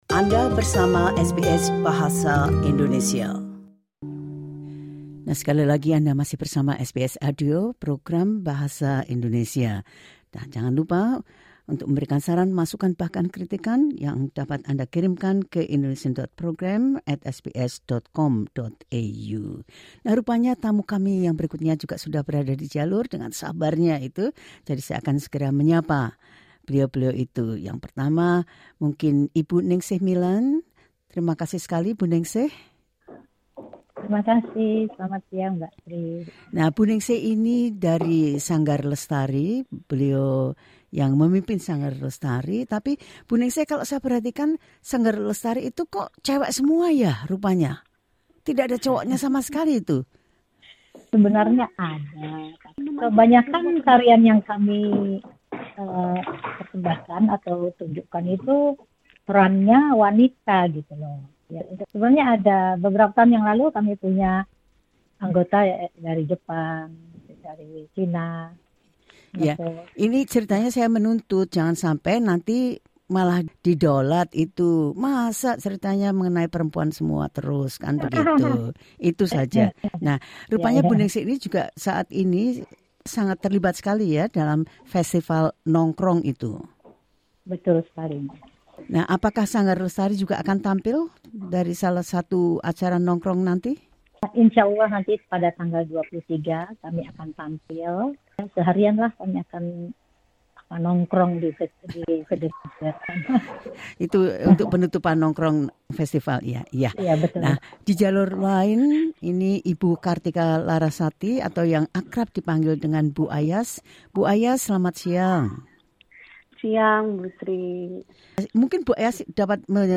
Sebagaiman dijelaskan dalam wawancara ini.